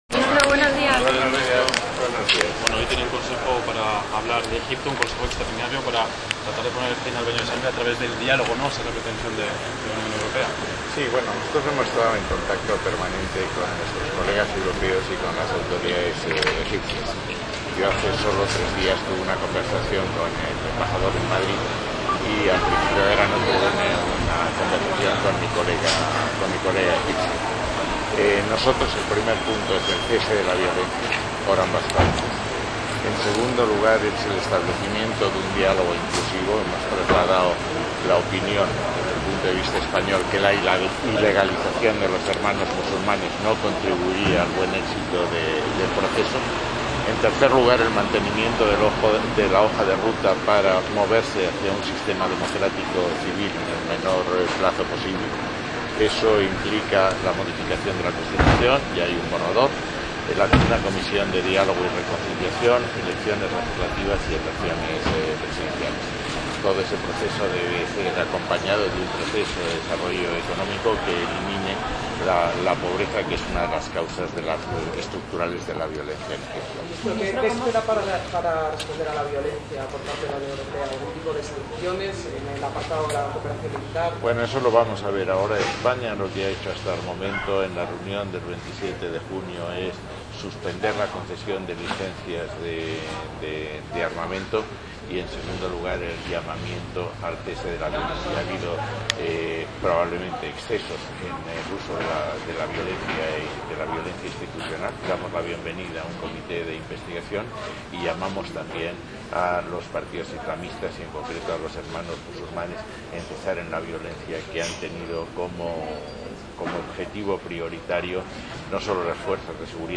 Archivo de audio con las declaraciones del ministro